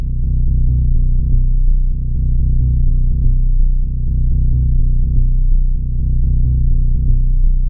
Tech House Bass Rhythm Sustained Melody.wav
Loudest frequency 53 Hz
tech_house_bass_rhythm_sustained_melody_g8q.ogg